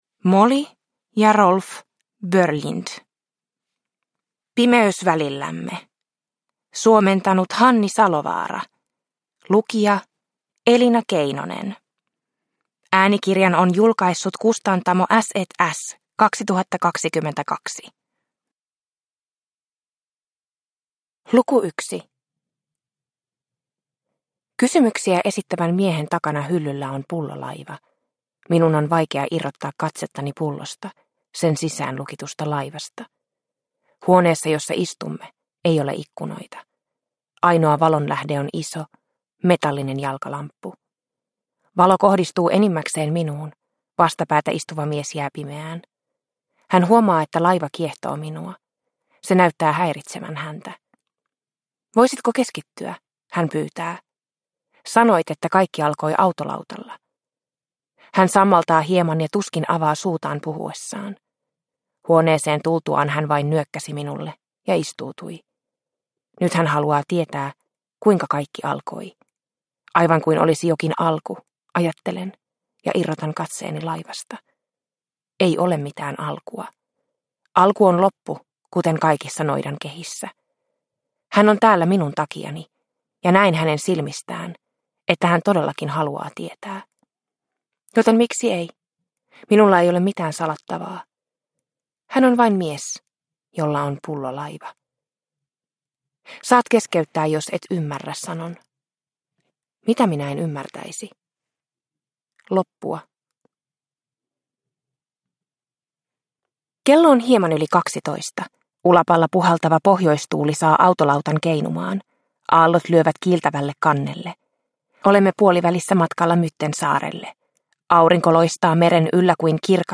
Pimeys välillämme – Ljudbok – Laddas ner
Pimeys välillämme on äänikirjaksi kirjoitettu tiivis, psykologinen trilleri.